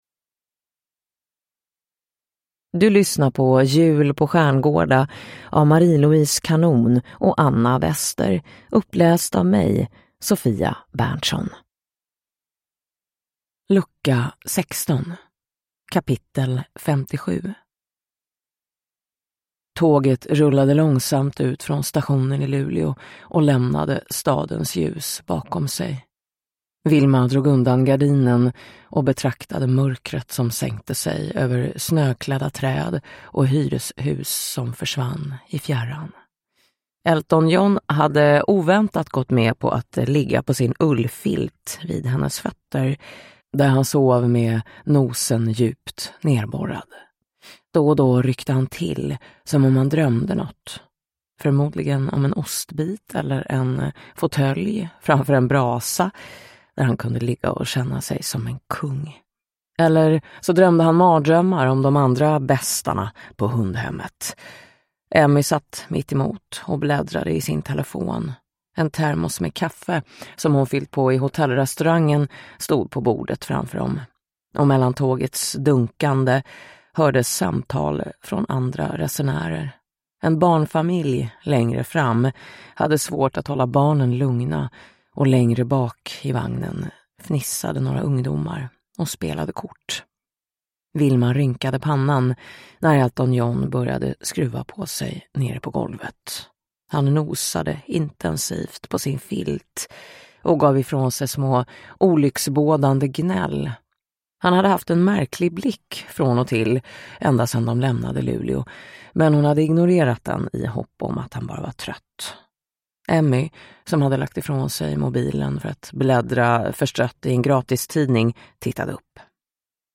Jul på Stjärngårda: Lucka 16 – Ljudbok